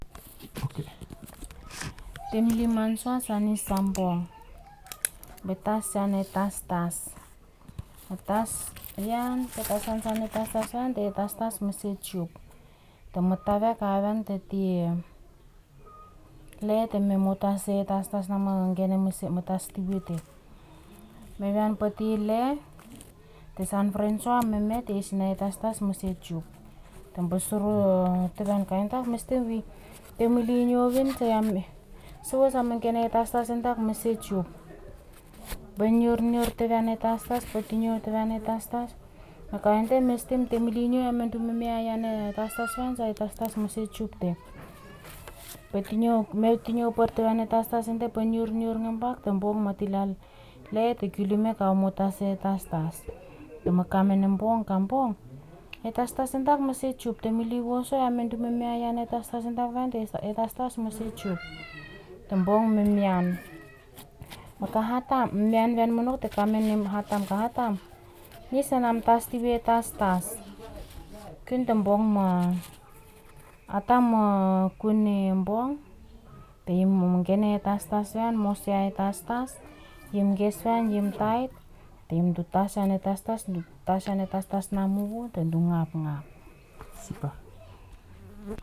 Storyboard elicitation of argument structure alternations (Storyboard: The old bench)
digital wav file recorded at 44.1 kHz/16 bit on Marantz PMD 620 recorder
Emiotungan, Ambrym, Vanuatu